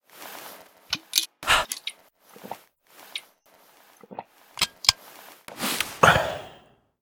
drink_flask.ogg